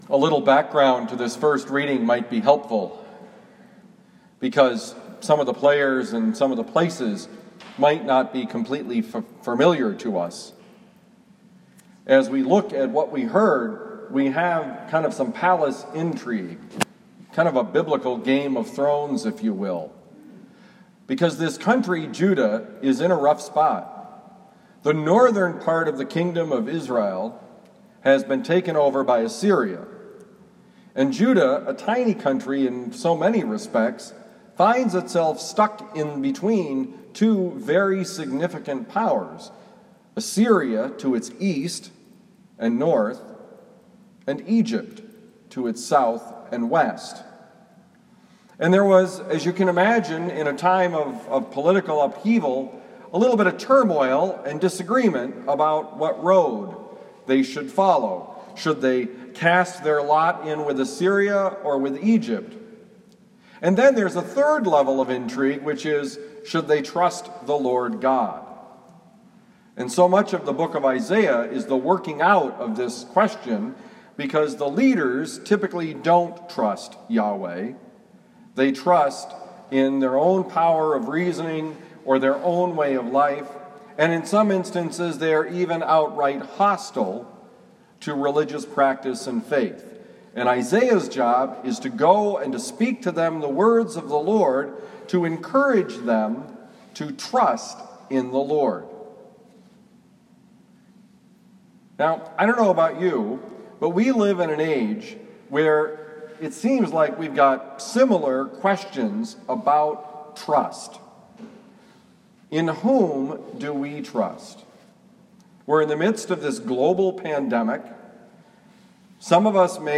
Homily given at Our Lady of Lourdes Parish, University City, Missouri